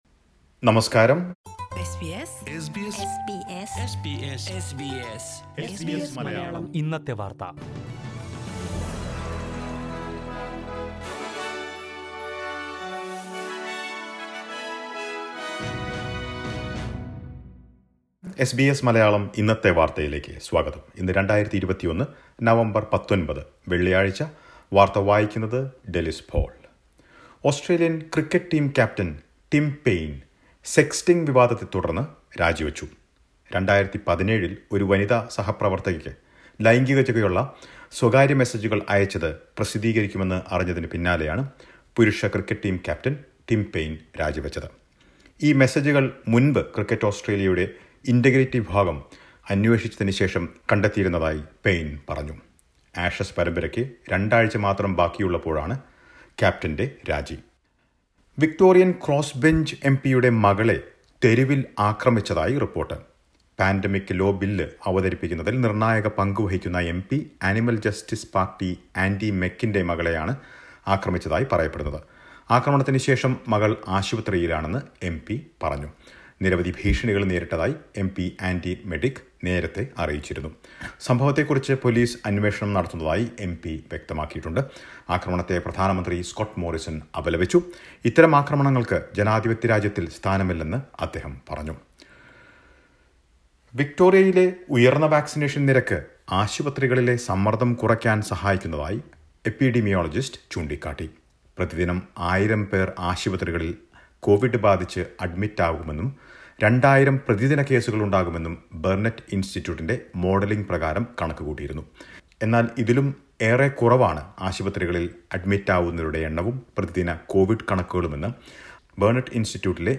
mlm_news_1911_bulletin.mp3